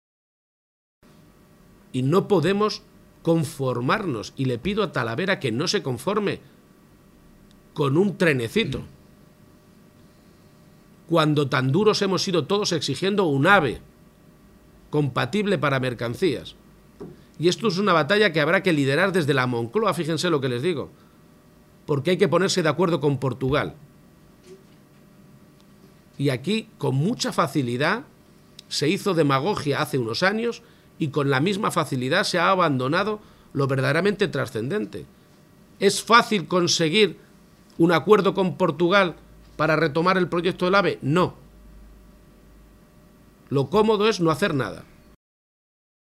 García-Page se pronunciaba de esta manera esta mañana, en Talavera de la Reina, tras reunirse con la Junta Directiva de la Federación Empresarial Talaverana (FEPEMPTA), en la ciudad de la cerámica.